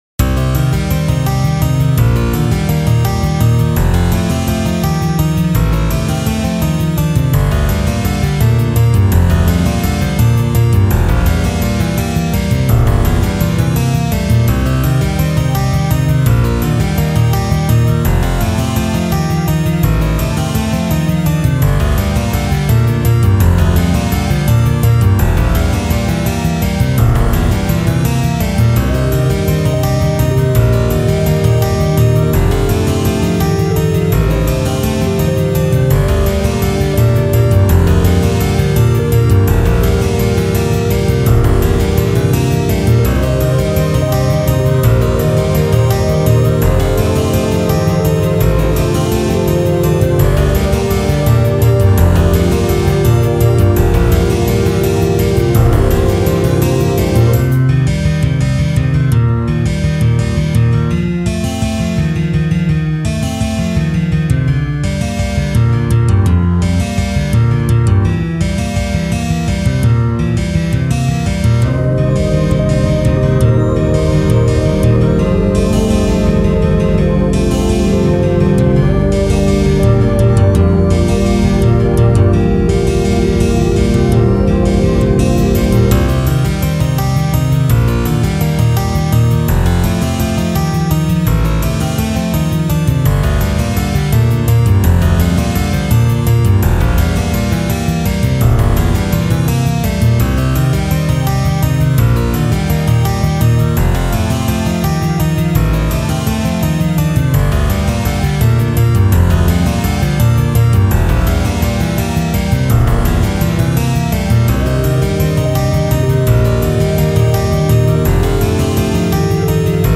23/16 time signature... madman composition....
2024 classico 5/4 to end things on